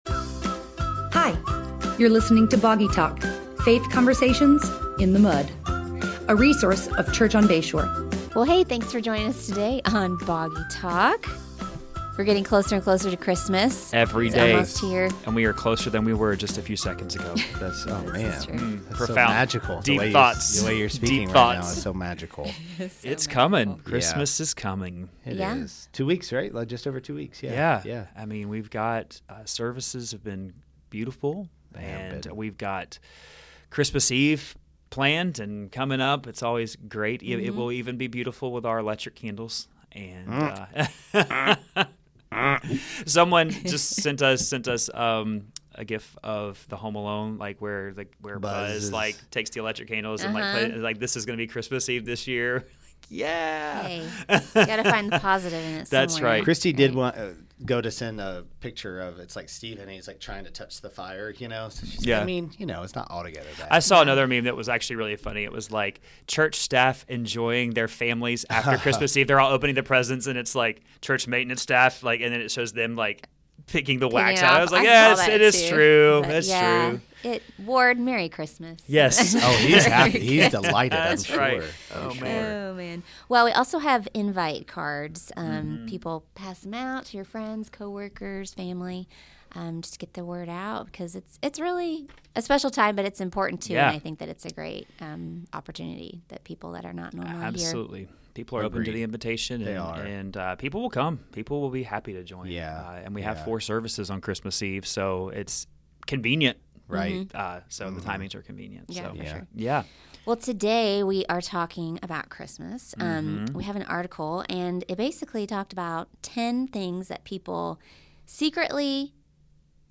We typically want every matter of faith and life to have crystal clear answers, but it isn’t always that easy. Recorded on the Boggy Bayou of Niceville, Florida